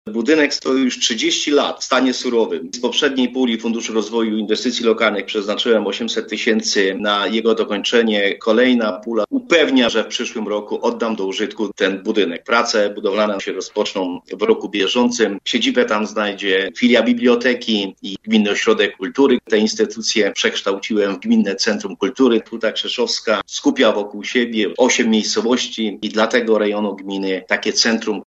Gmina Harasiuki zadba o nowe lokum dla Gminnego Ośrodka Kultury i filii biblioteki. Instytucje te połączone w jedną znajdą swoją siedzibę w Hucie Krzeszowskiej, w budynku który na dokończenie czeka od dawna. Mówi wójt Gminy Harasiuki Krzysztof Kiszka